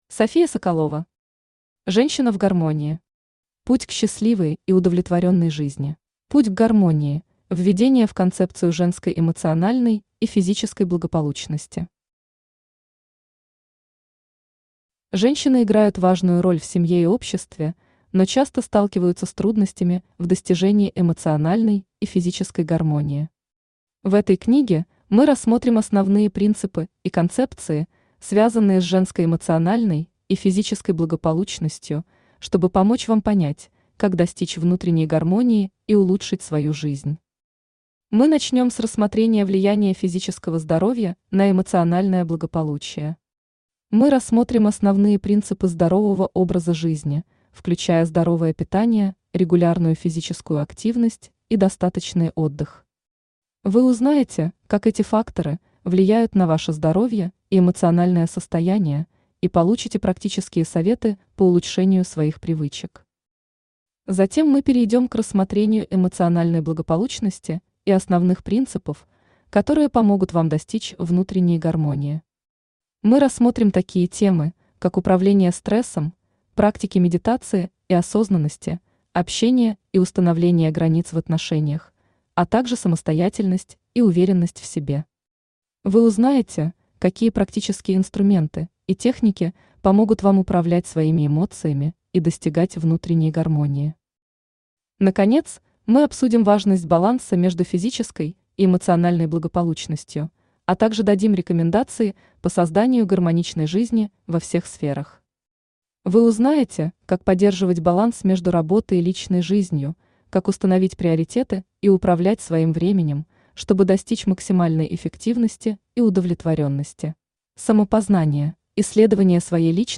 Аудиокнига Женщина в гармонии. Путь к счастливой и удовлетворенной жизни | Библиотека аудиокниг
Путь к счастливой и удовлетворенной жизни Автор София Соколова Читает аудиокнигу Авточтец ЛитРес.